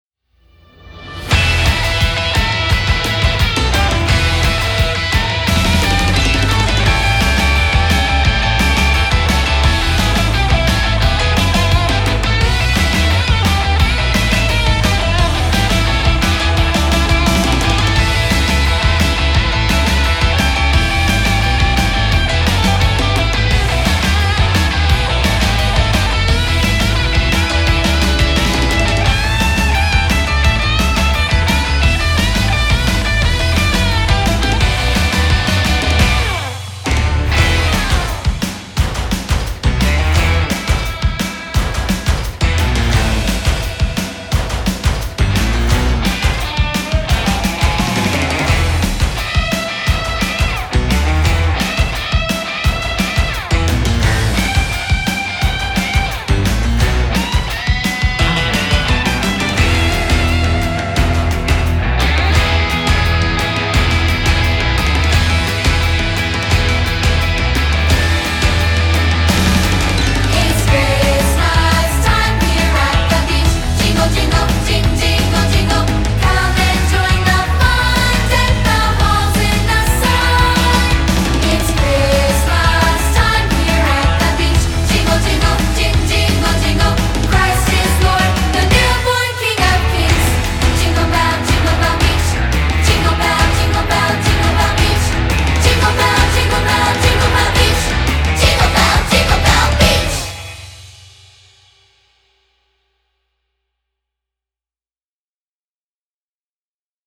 Voicing: Unison|2-Part